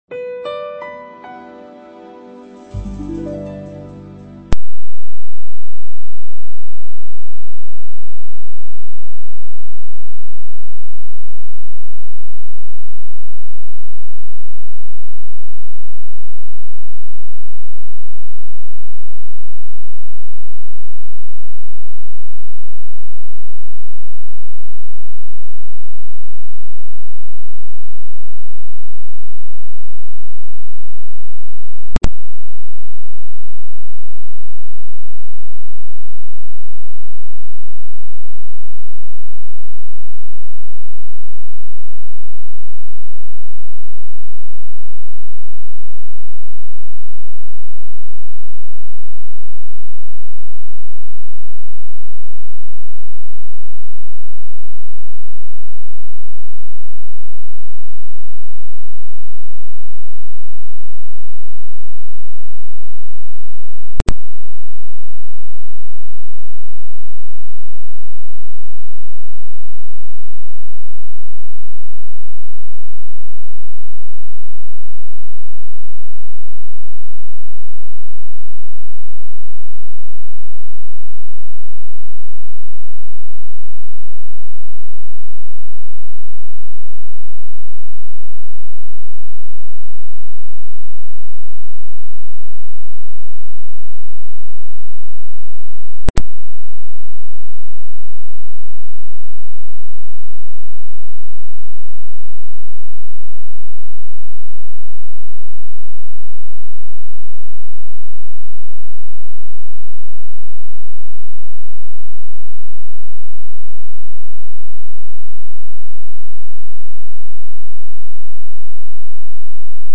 tutoría
Video Clase